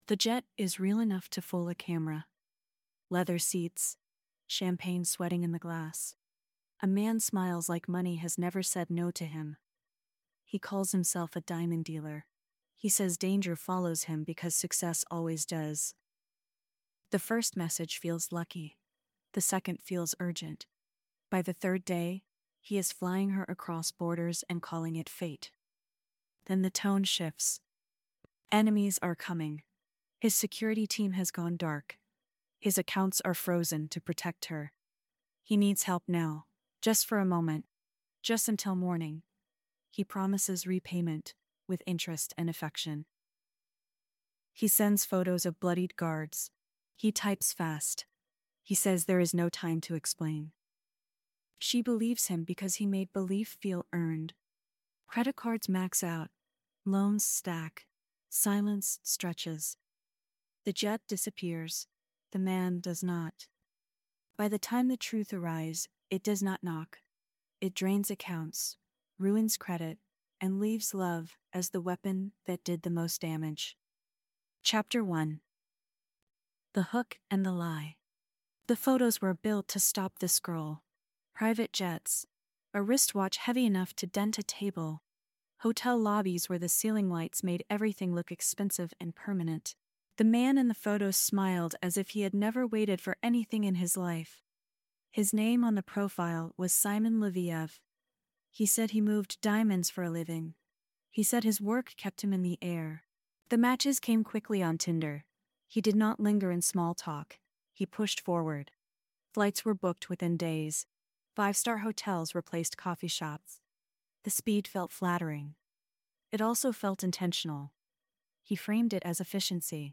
A three-chapter true-crime narrative examining how Shimon Hayut, posing as a wealthy diamond mogul, used dating apps to manufacture intimacy, stage danger, and extract money from women across countries. Told in a forensic, grit-driven style, the series tracks how luxury became a prop, fear became leverage, and love became the mechanism that moved cash, leaving victims with debt, shame, and long-term damage even after the lie was exposed.